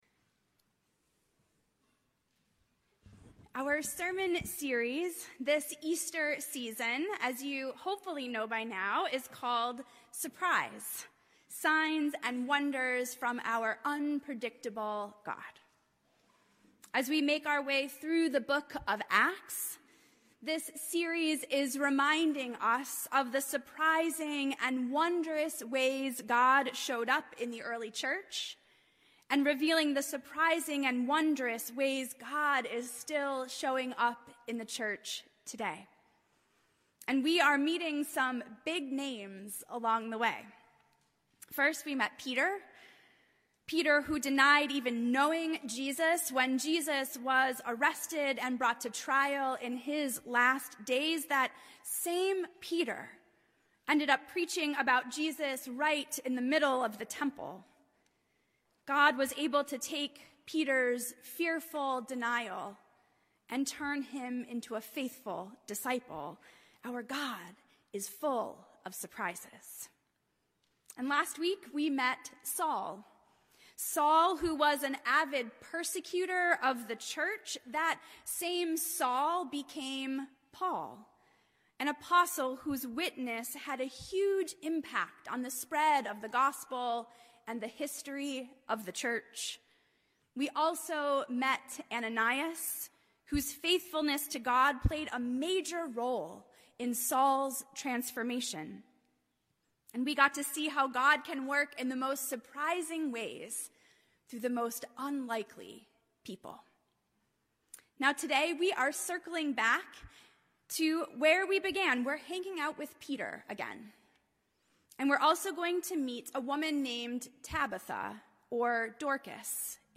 A message from the series "Eastertide 2022: Surprise!."